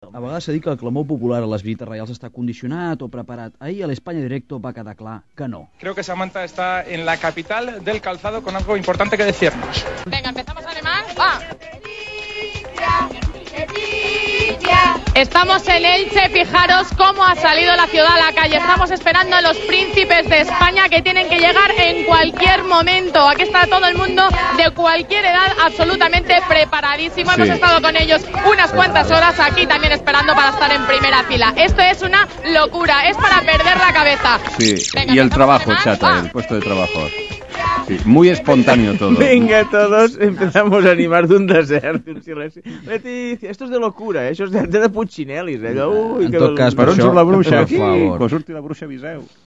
Al programa radiofònic "España en directo" es va sentir aquest document, que després el programa "Alguna pregunta més?" de Catalunya Ràdio va comentar. Fragment de "España en directo" Un bon exemple el tenim en la condemna que va patir TVE per la vaga general del 14 de juny de 2002.